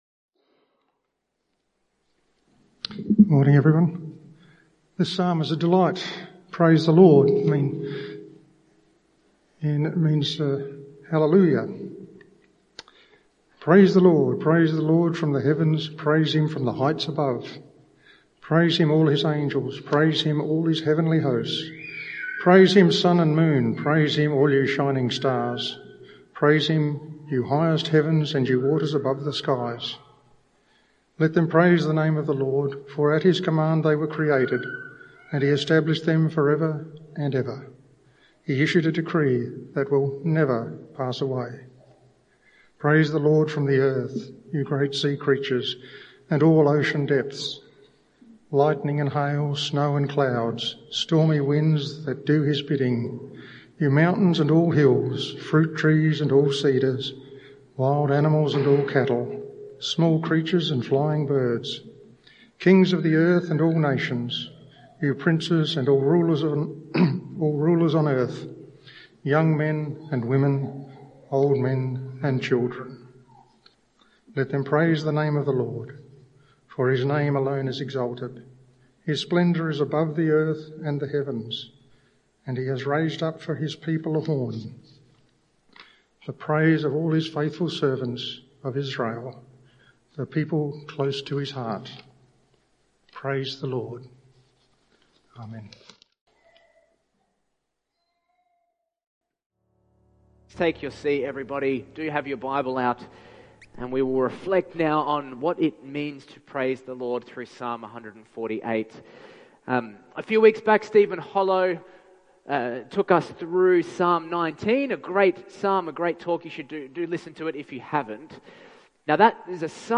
This summary is generated using AI from the sermon manuscript.